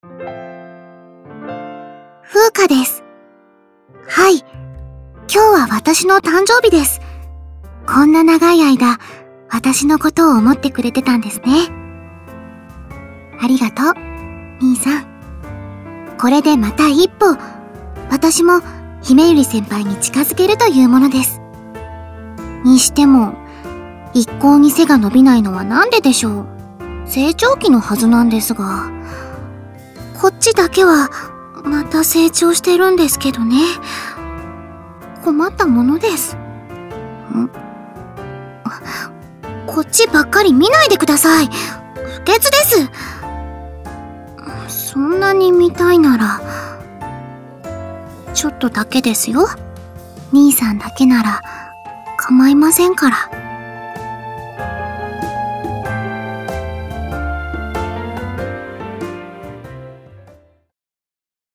夏本諷歌誕生日記念ボイスを公開しました！